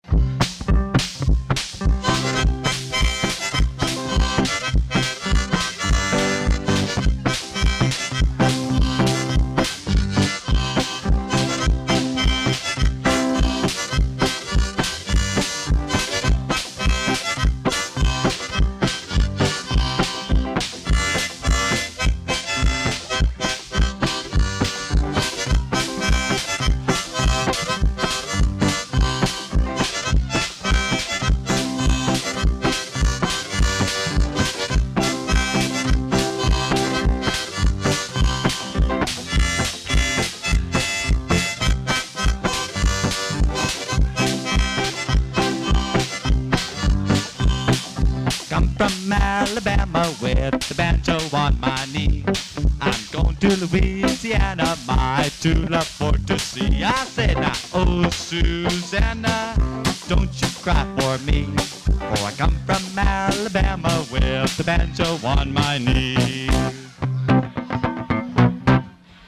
Live at Joe's Joint Vol. 2